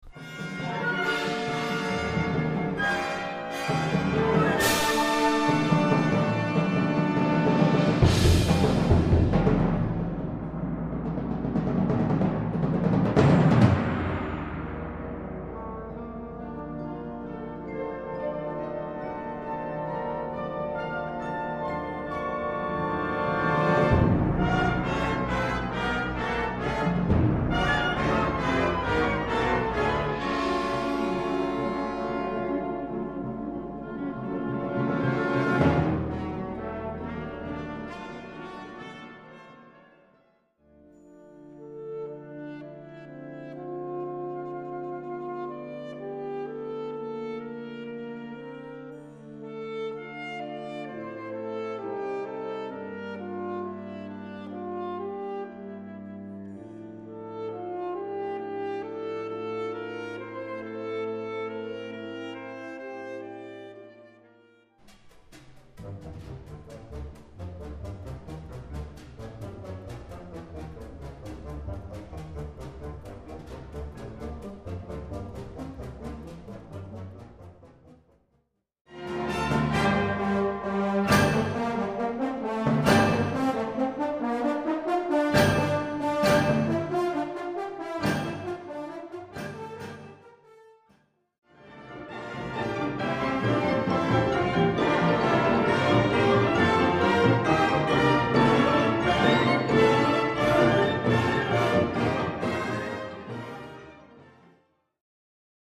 Categoría Banda sinfónica/brass band
Subcategoría Música contemporánea original (siglo XX y XXI)
Instrumentación/orquestación Ha (banda de música)